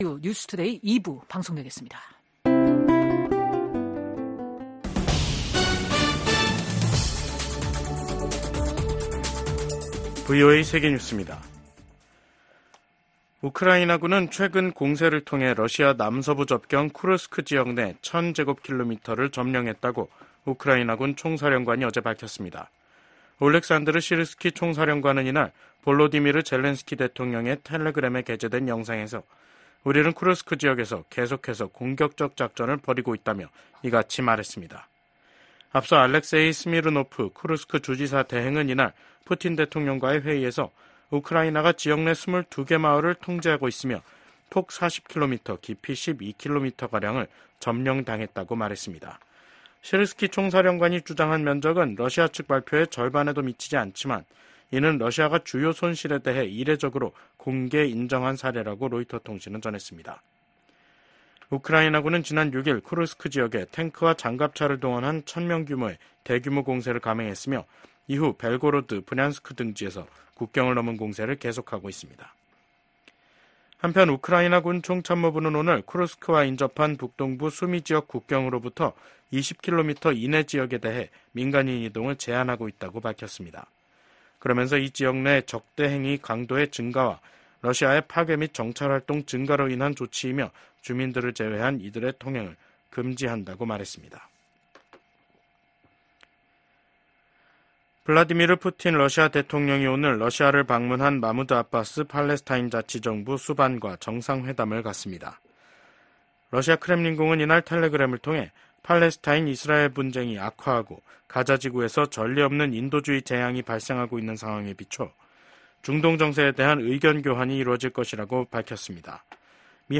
VOA 한국어 간판 뉴스 프로그램 '뉴스 투데이', 2024년 8월 13일 2부 방송입니다. 북러 군사 밀착이 우크라이나뿐 아니라 인도태평양 지역 안보에도 영향을 미칠 것이라고 미국 백악관이 지적했습니다.